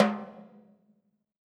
BTIMBALE H1I.wav